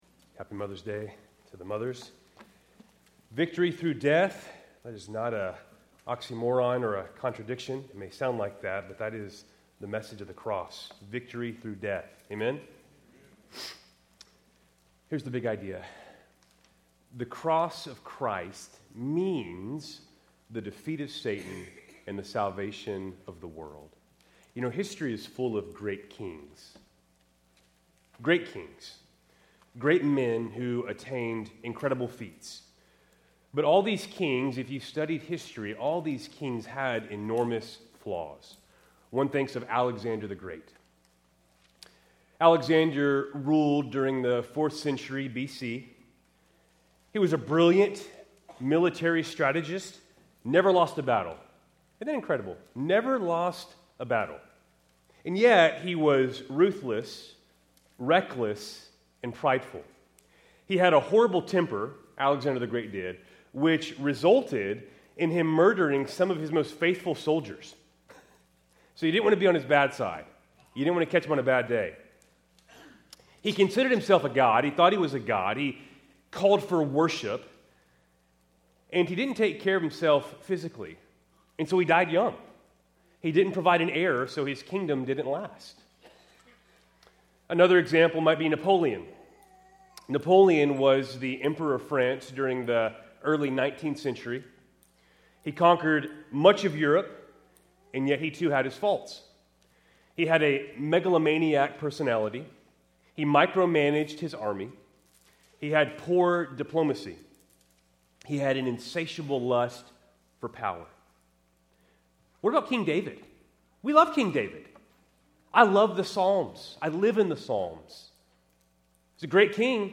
Keltys Worship Service, May 11, 2025 (Mother’s Day)